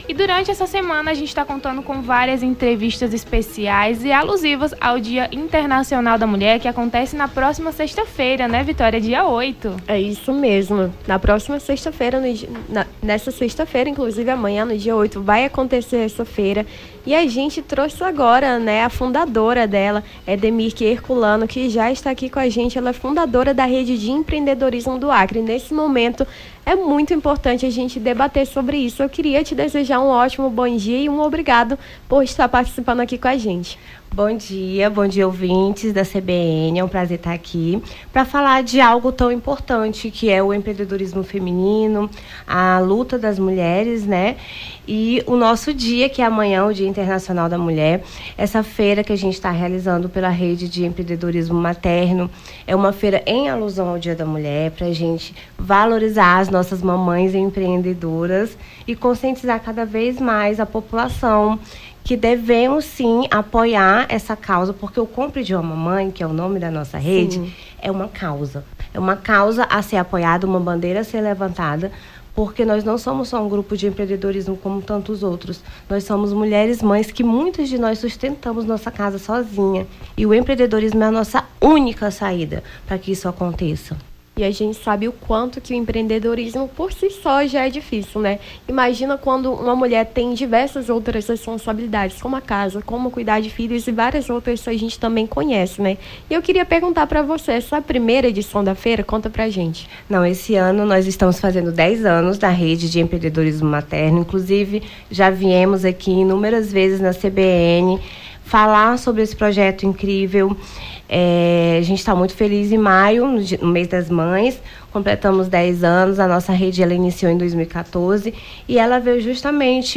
Nome do Artista - CENSURA - ENTREVISTA FEIRA MULHER EMPREENDEDORA (07-03-24).mp3